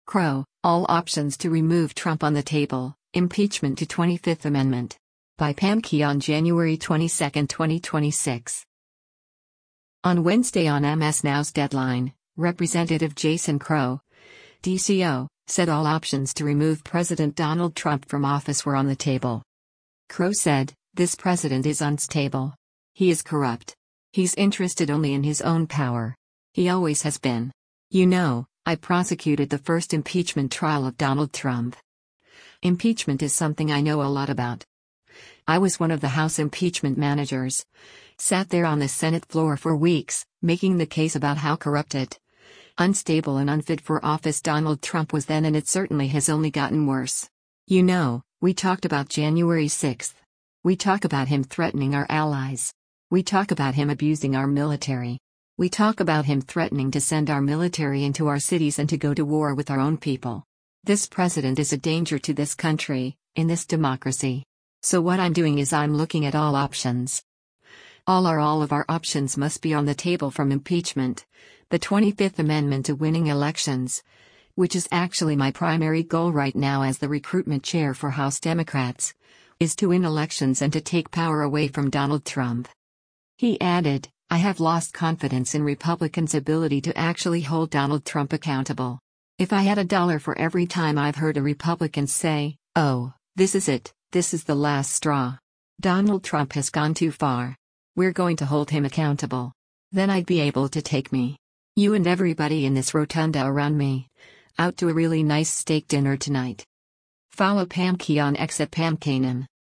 On Wednesday on MS NOW’s “Deadline,” Rep. Jason Crow (D-CO) said all options to remove President Donald Trump from office were on the table.